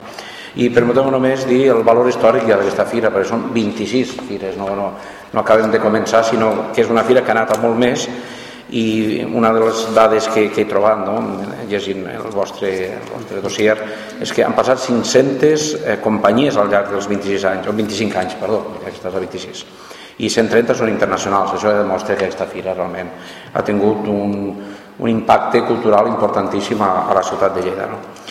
El vicepresident primer de la Diputació de Lleida, Miquel Padilla, ha participat aquest dimarts en la presentació de la 26a Fira de Teatre de Titelles que se celebrarà a la ciutat de Lleida els dies 1, 2 I 3 de maig.
Miquel-Padilla-Fira-titelles-valor-historic.mp3